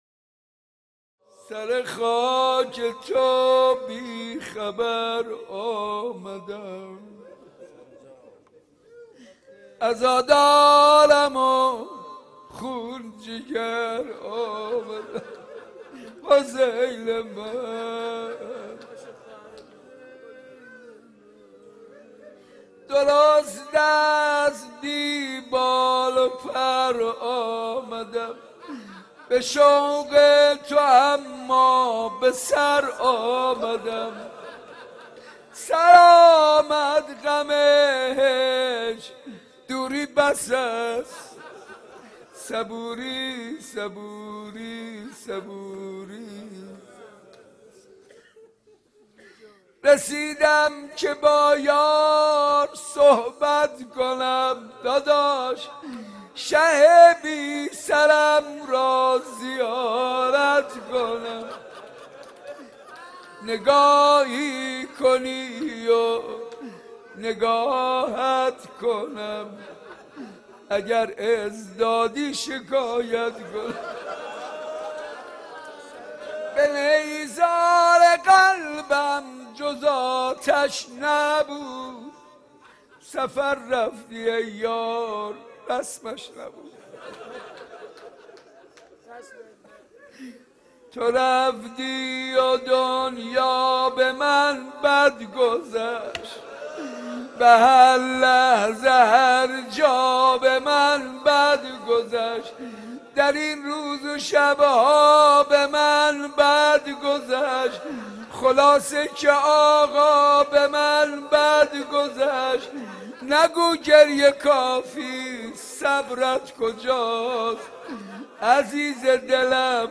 نوحه
نوحه مداحی